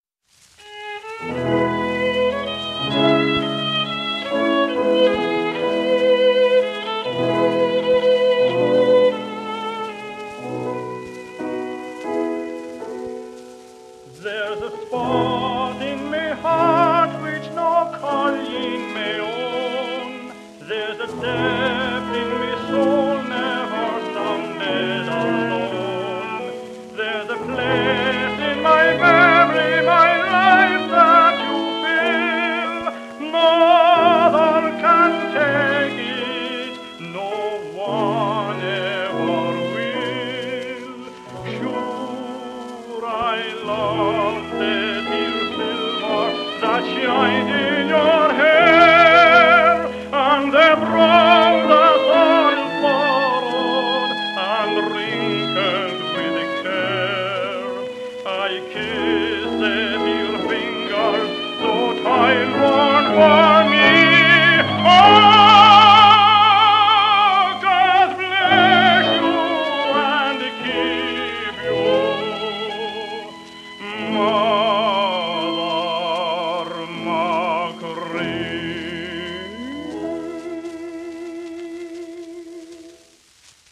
he himself always used the term 'Operatic tenor'